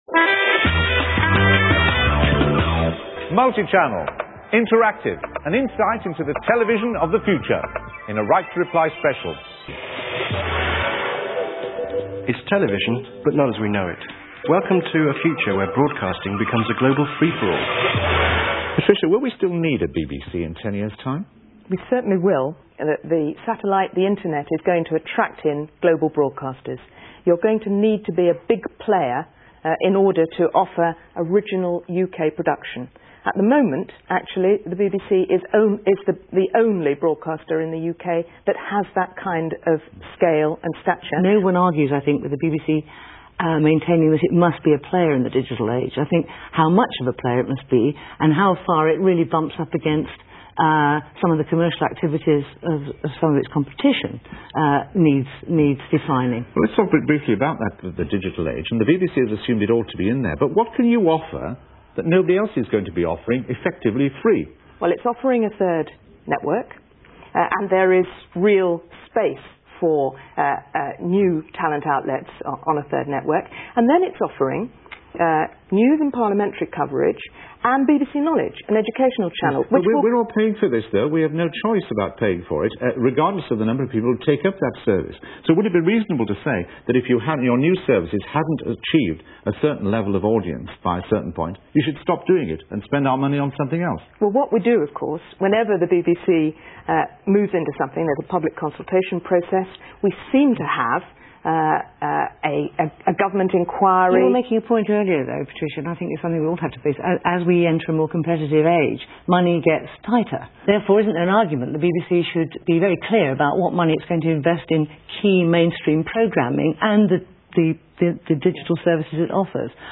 The BBC was represented by Patricia Hodgson, Director of Policy & Planning.
The discussion